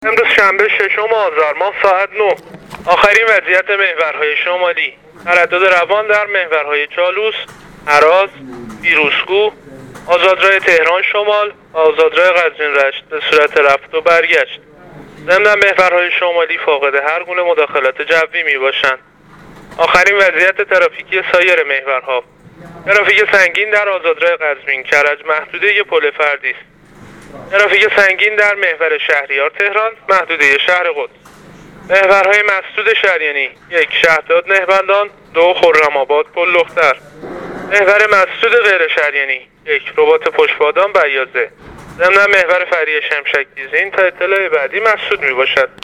گزارش رادیو اینترنتی از آخرین وضعیت ترافیکی جاده‌ها تا ساعت ۹ ششم آذر؛